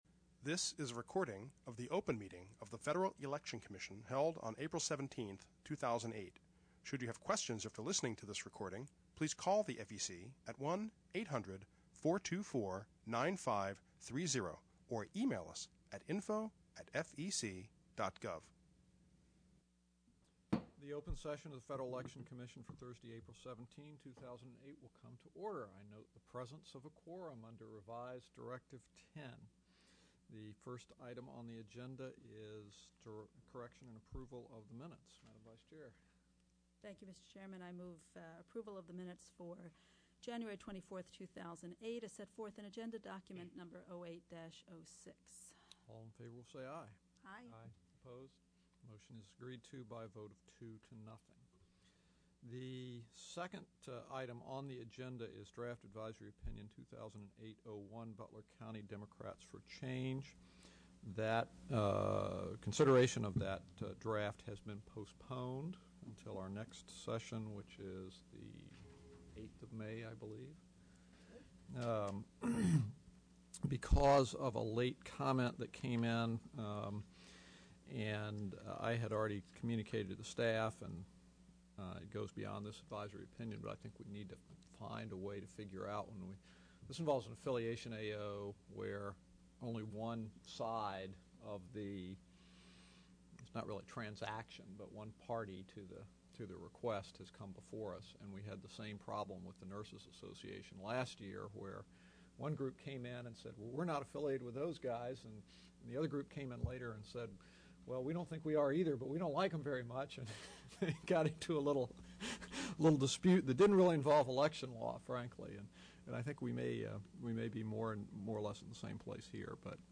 April 17, 2008 open meeting | FEC
[Audio File of Entire Meeting, 3.1MB,